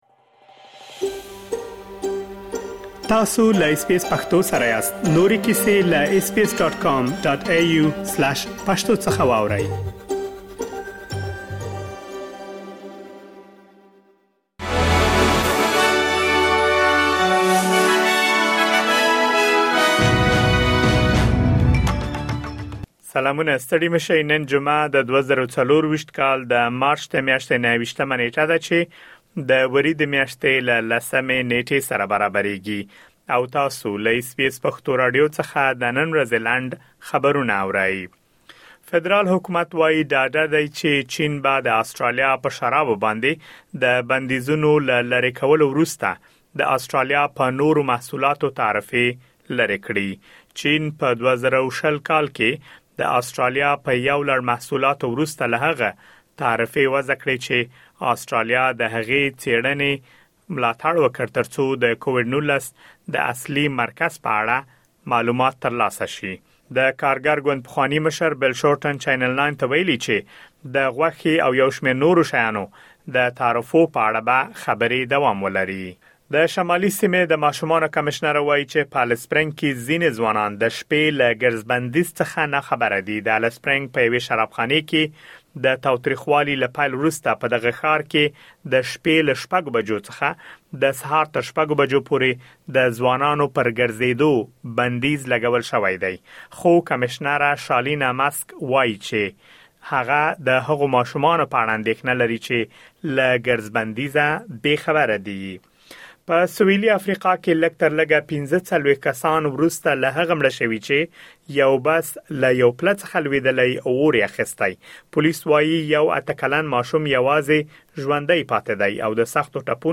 د اس بي اس پښتو راډیو د نن ورځې لنډ خبرونه|۲۹ مارچ ۲۰۲۴
اس بي اس پښتو راډیو د نن ورځې لنډ خبرونه دلته واورئ.